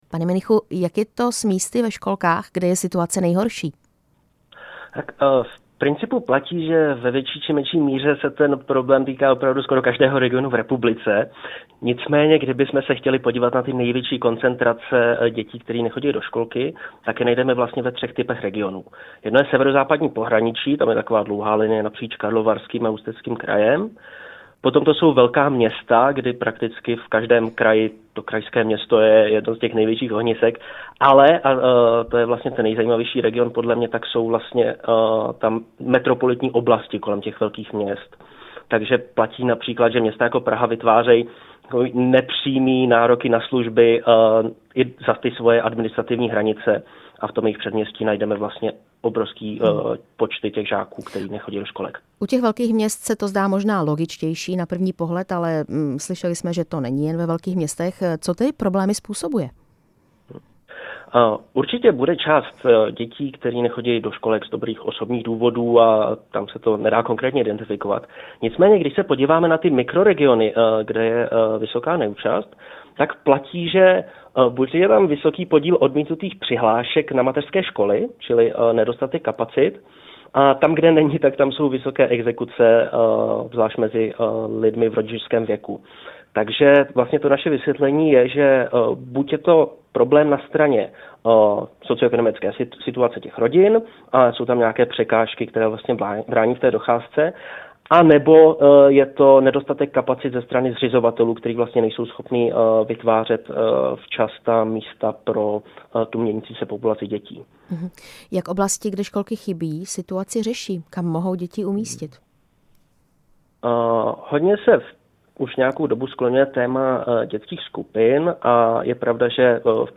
Rouhovor